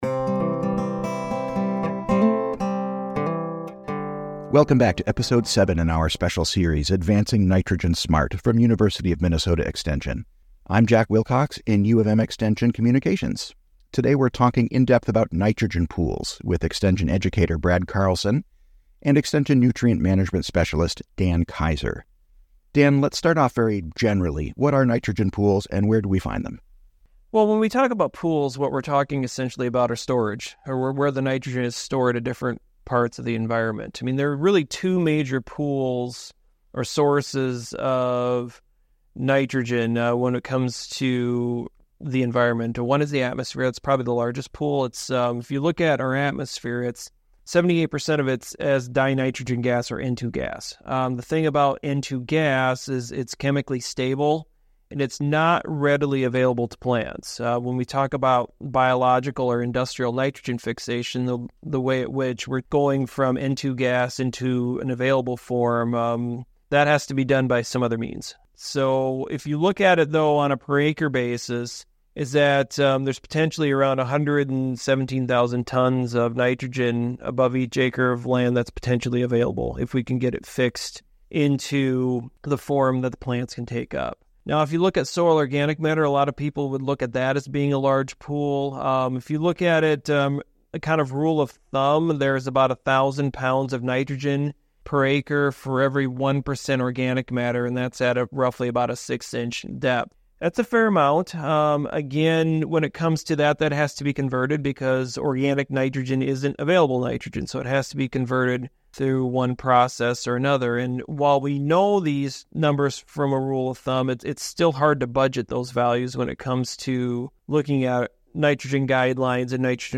In this episode of the Advancing Nitrogen Smart series, our two experts discuss nitrogen pools.